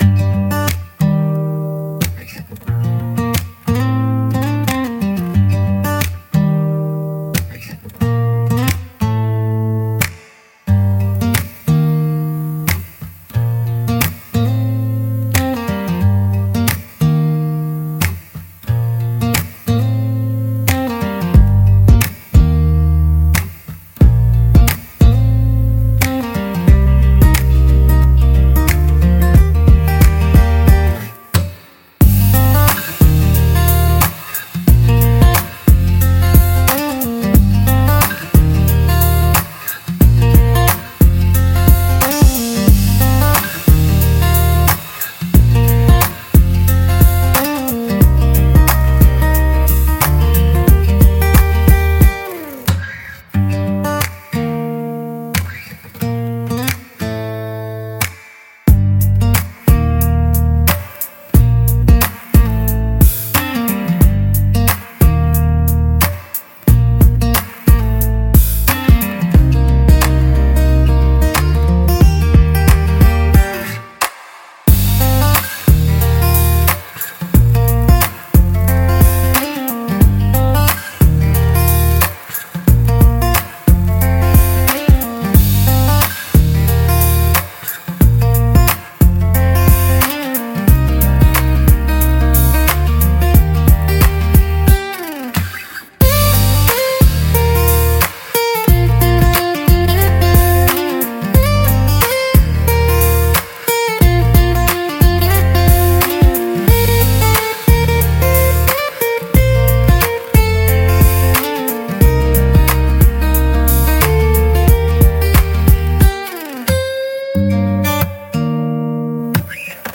街ジャンルは、ゆったりとしたリズムと明るく親しみやすい曲調が特徴のオリジナルジャンルです。
穏やかでリラックスできる雰囲気を持ち、日常生活や会話シーンによくマッチします。
聴く人に快適で穏やかな気持ちをもたらし、ナチュラルで親近感のある空気感を演出します。